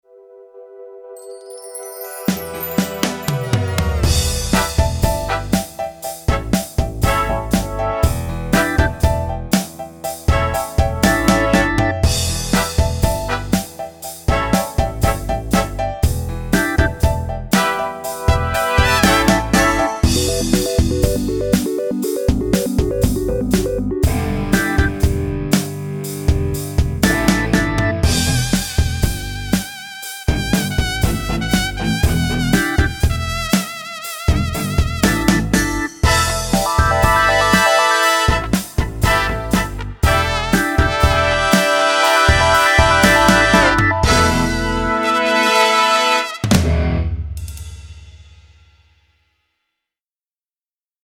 Установив Sonar и загрузив в него файл с патчами, я записал небольшое демо (Sonar - не моя привычная DAW, поэтому очень небольшое).
3. Roland BK-5 as sound module with Sonar 1,2 Мб